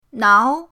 nao2.mp3